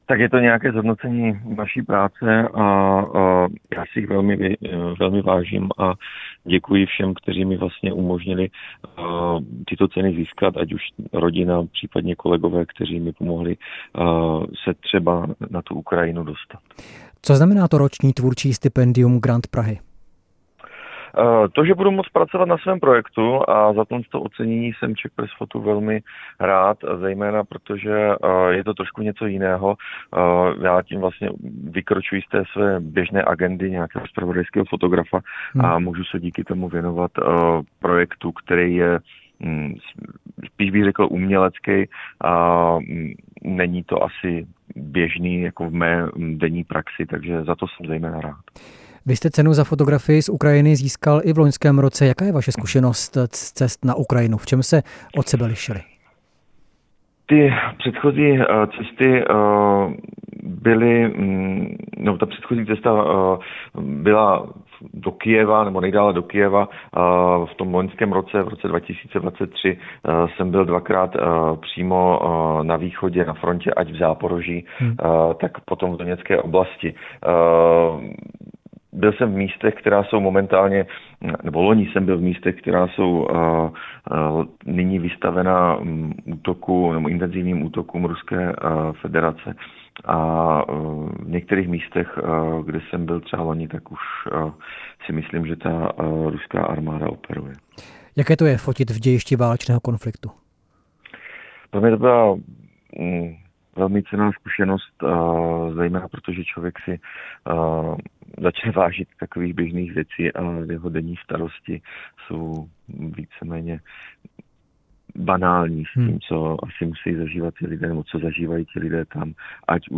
Živé vysílání